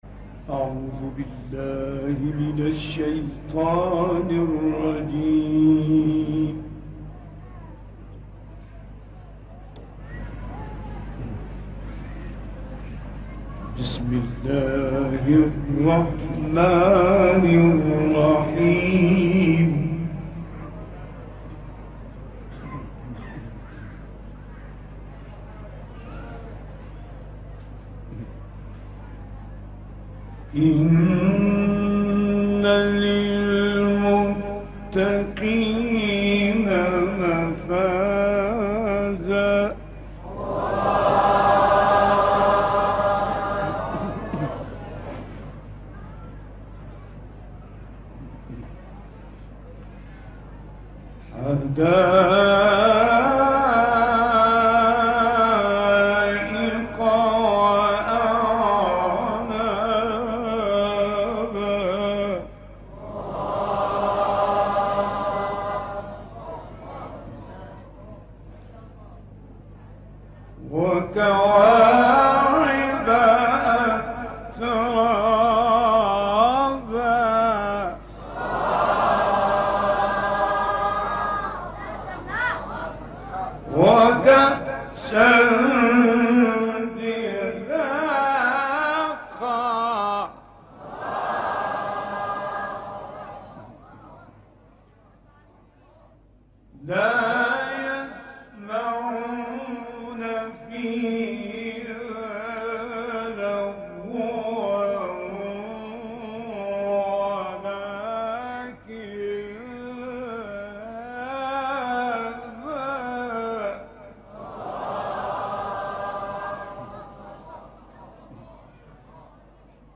Galveş’in Yezd kentindeki Kur’an kıraati
Tahran, 26 Eylül 2016 - Dünyaca ünlü kâri Mustafa Ragıp Galveş’in 2002 yılına ait nadir kıraatlerinden birini okurlarımızla paylaşıyoruz.
Üstad Galveş’in İran’ın Yezd kentindeki kıraatinin ses kaydını okurlarımızla paylaşıyoruz.